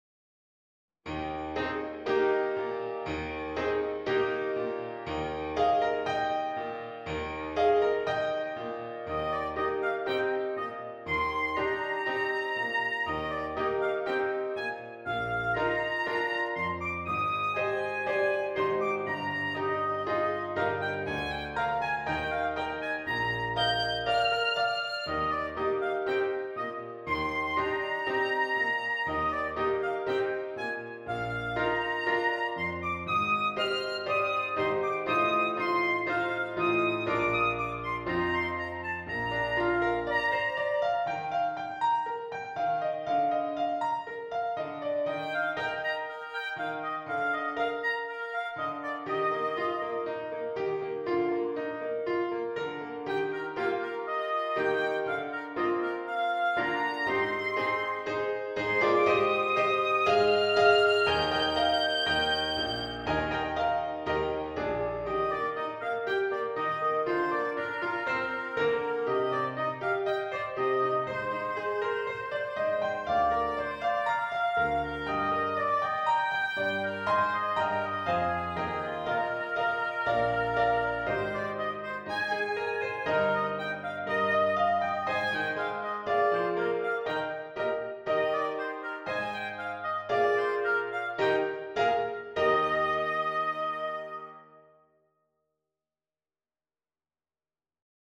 short solos
Oboe version
• Piano score